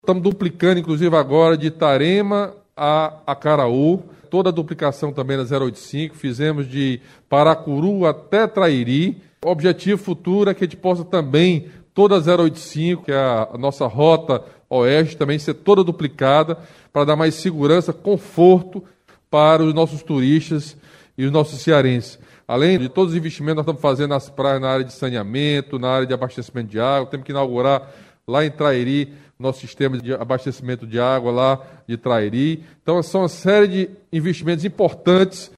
O governador Camilo Santana destacou importantes investimentos que o Governo do Ceará está executando nos municípios das Regiões dos Litoral Oeste e Norte do Ceará.